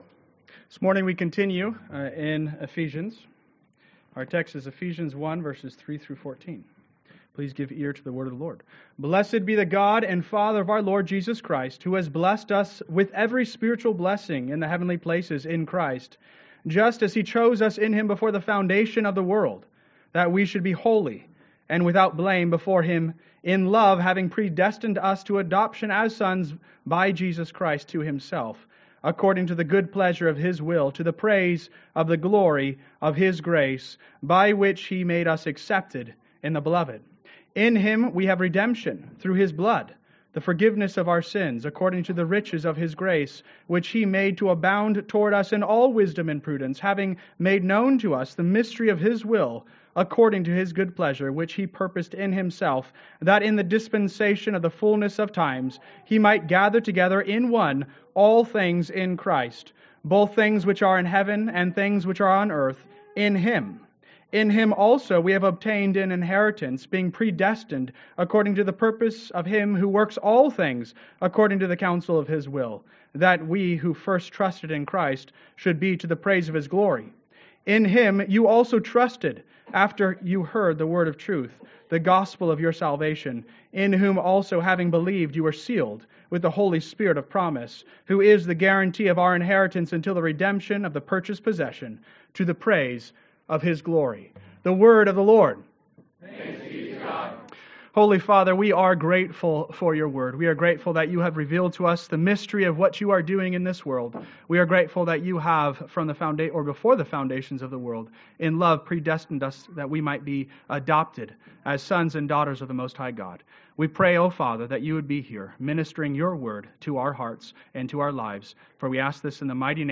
Sermon Outline: 02-09-25 Outline Ephesians 1b (Alpha & Omega)